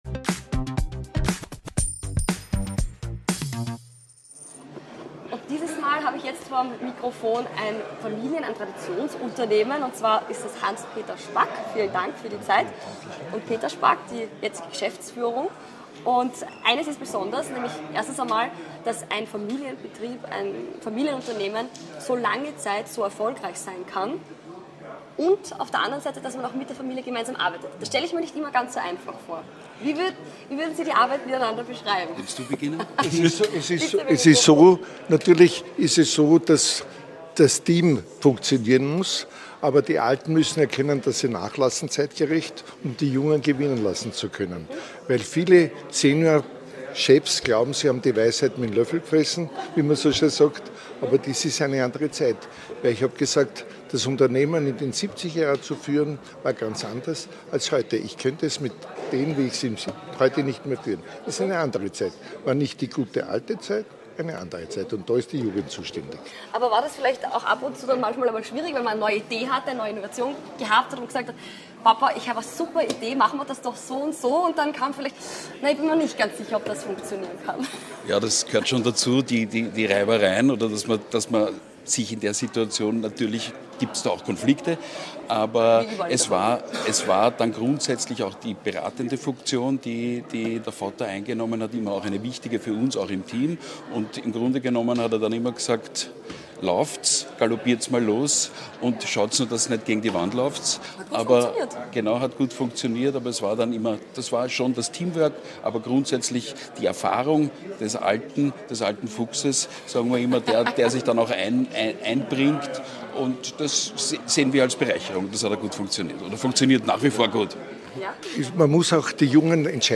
Podcasts live vom 40. CASH Handelsforum in St. Wolfgang.